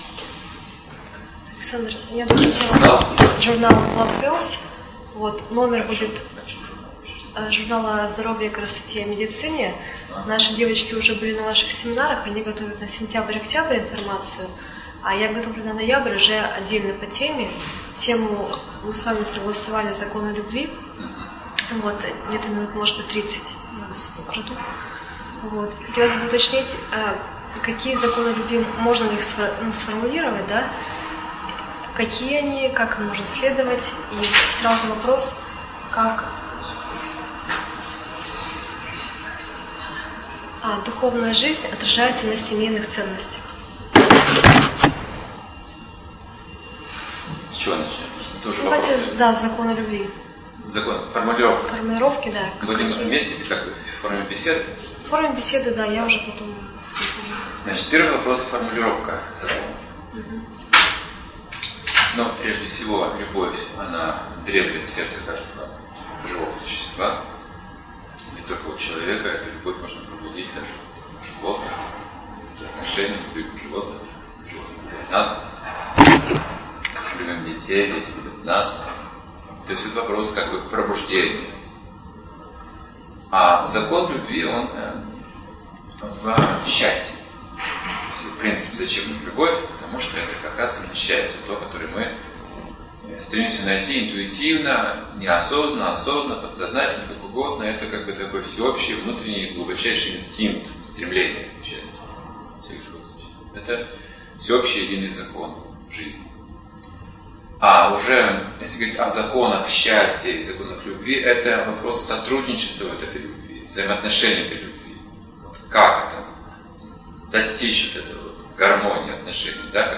Интервью для журнала Владхелс. Законы любви (2015, Владивосток)